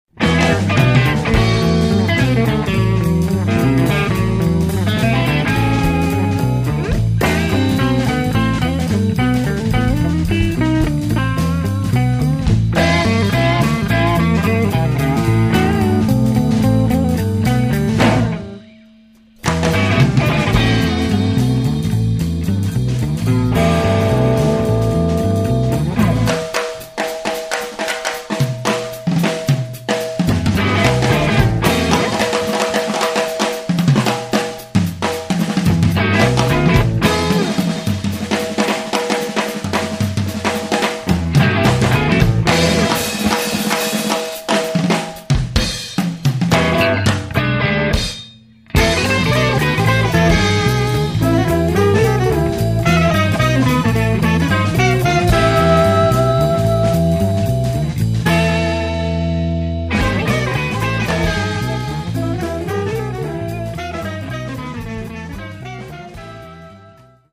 bass
drums